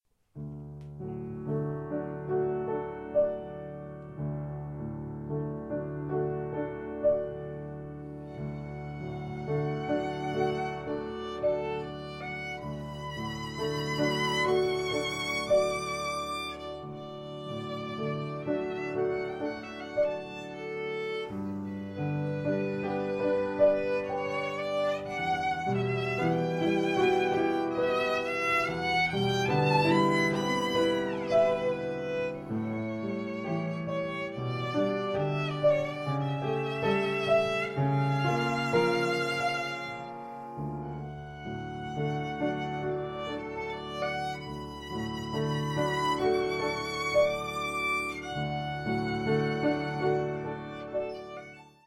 Hörproben - Klassik